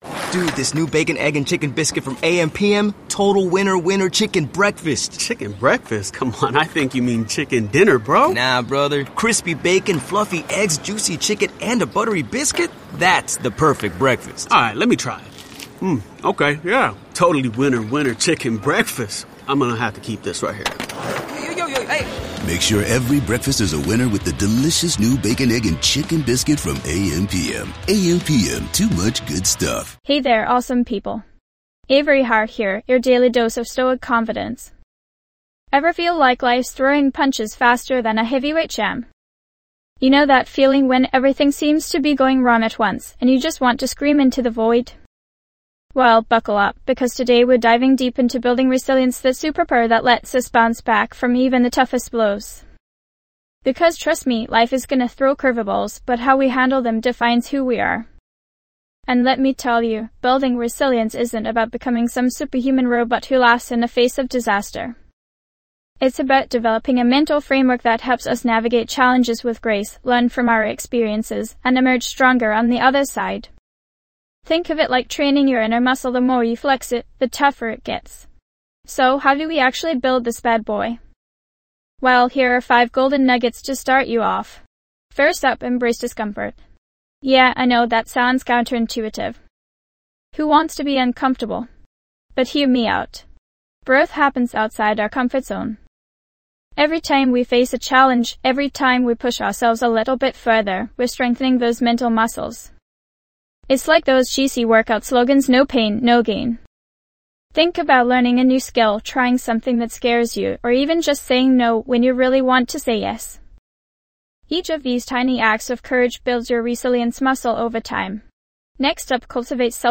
Podcast Category:. Personal Development, Self-Help, Inspirational Talks, Philosophy
This podcast is created with the help of advanced AI to deliver thoughtful affirmations and positive messages just for you.